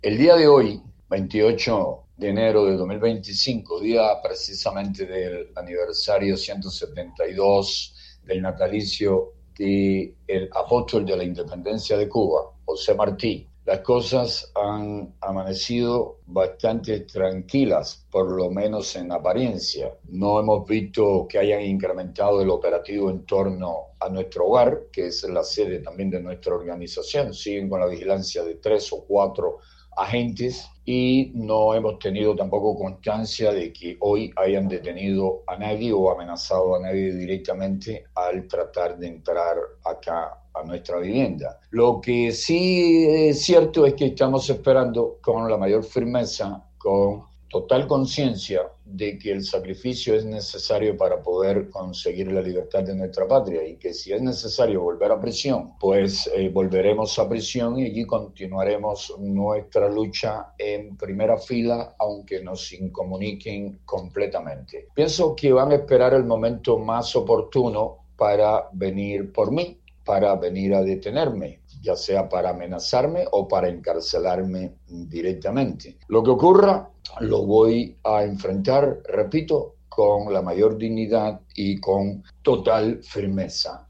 Declaraciones de José Daniel Ferrer a Martí Noticias